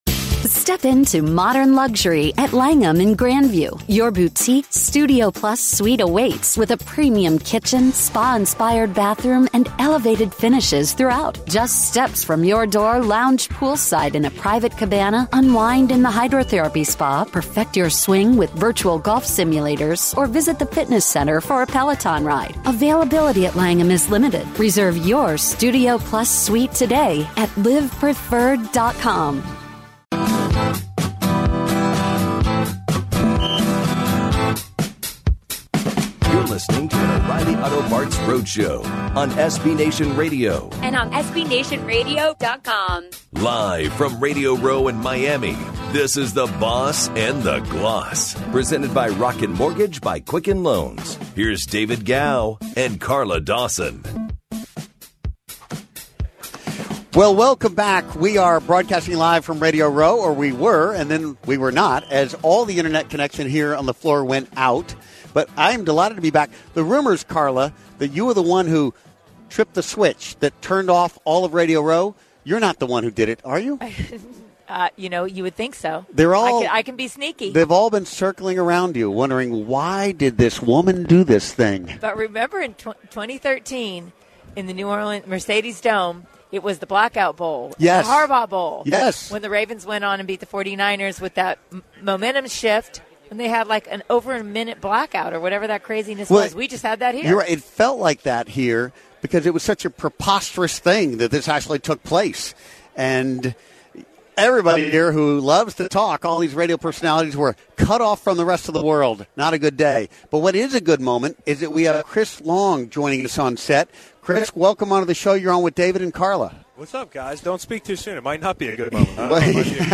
Former NFL DE Chris Long joins The Boss & The Gloss Live on Radio Row